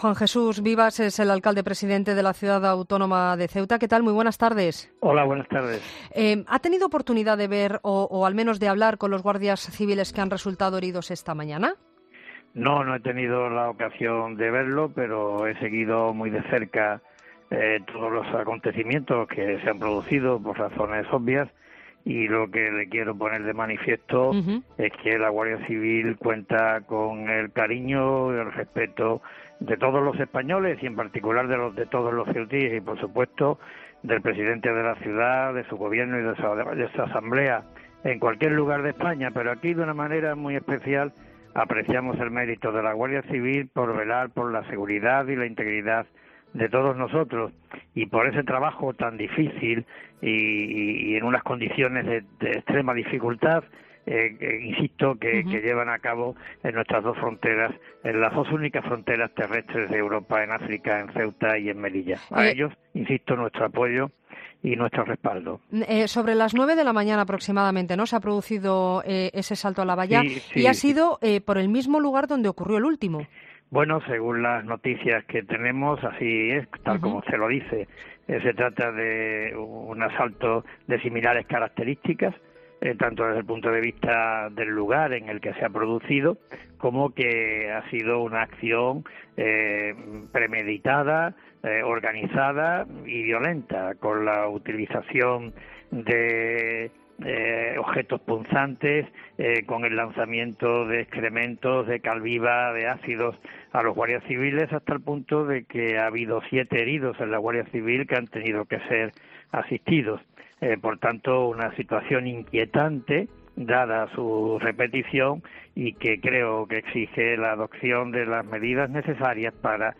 Entrevista a Juan Jesús Vivas, alcalde presidente de Ceuta